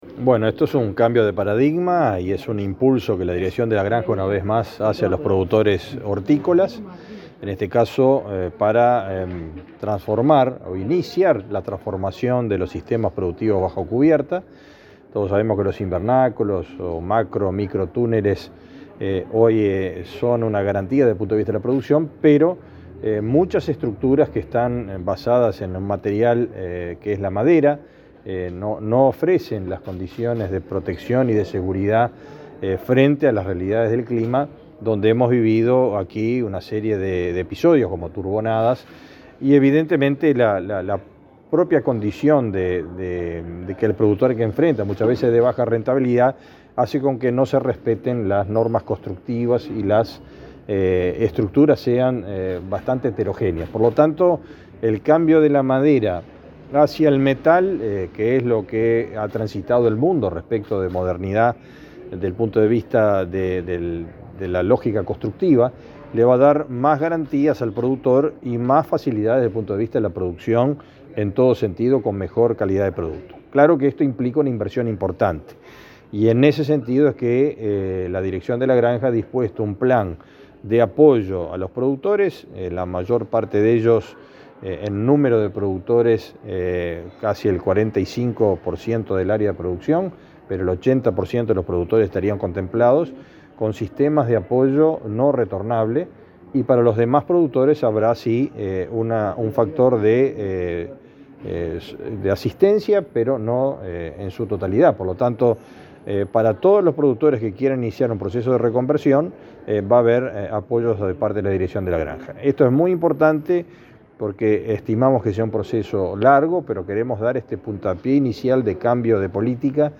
Declaraciones del ministro de Ganadería, Fernando Mattos
Declaraciones del ministro de Ganadería, Fernando Mattos 27/02/2024 Compartir Facebook Twitter Copiar enlace WhatsApp LinkedIn El ministro de Ganadería, Fernando Mattos, y el director general de la Granja, Nicolás Chiesa, anunciaron, este martes 27 en una conferencia de prensa, nuevos planes de apoyo al sector granjero. Luego, el secretario de Estado dialogó con la prensa.